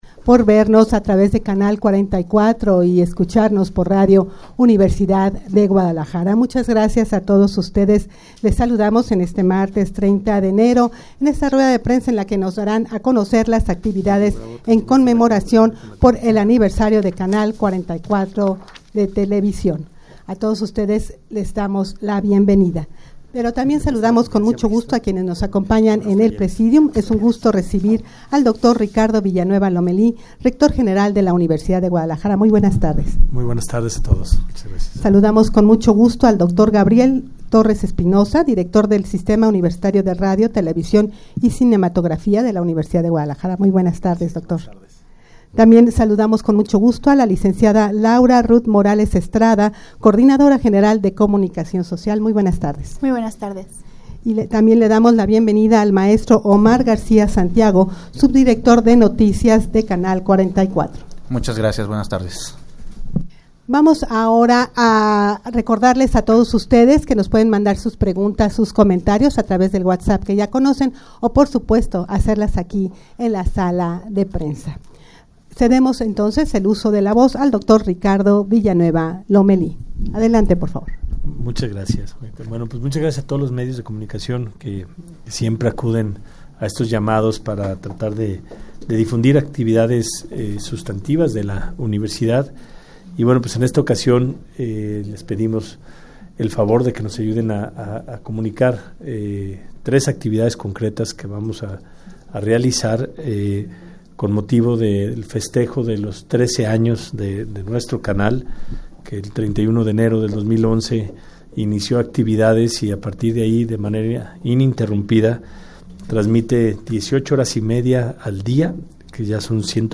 Audio de la Rueda de Prensa
rueda-de-prensa-para-anunciar-las-actividades-en-conmemoracion-por-el-xiii-aniversario-de-canal-44-tv.mp3